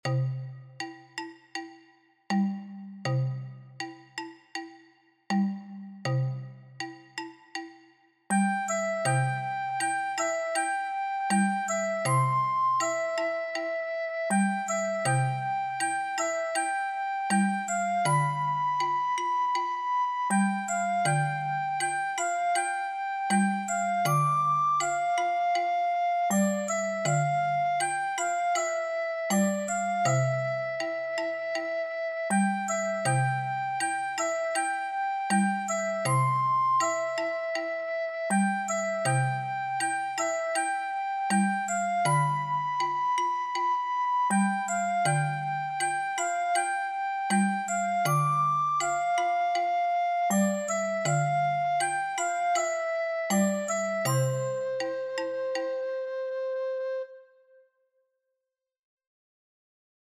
Un pouco máis lento (80)
Donde_vas_con_manton_de_manila_lenta.mp3